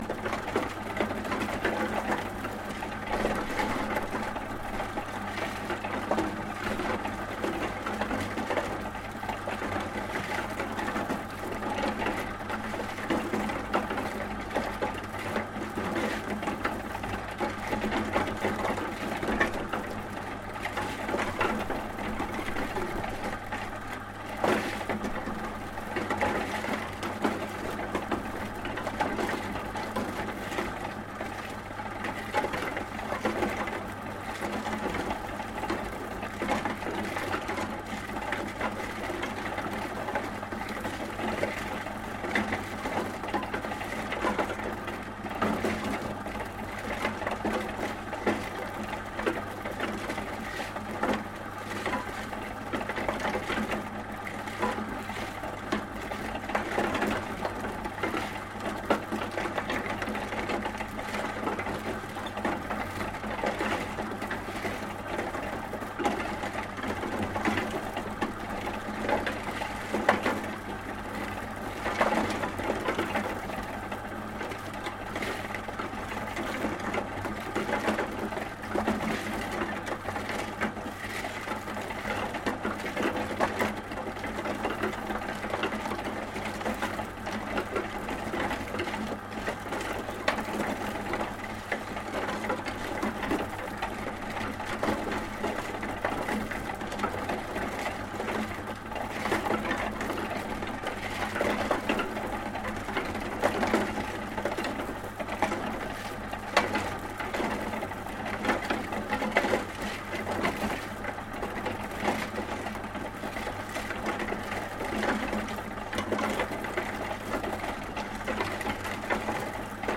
Звуки цемента
Шум передвижной бетономешалки